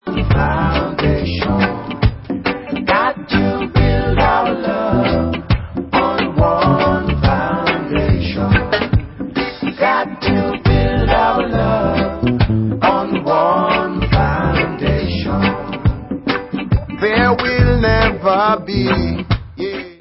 sledovat novinky v oddělení World/Reggae